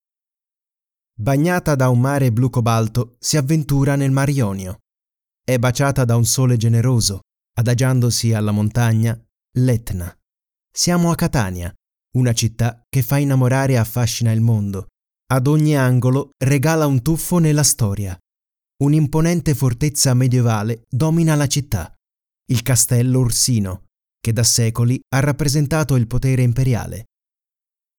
Italian male voice, Voce italiana, italian speaker, dubbing, doppiaggio italiano, e-learning italiano.
Sprechprobe: Werbung (Muttersprache):
My voice is clear, deep and i can change it as you want for commercials, e-learning, cartoon and corporate.
etna RODE narrazione.mp3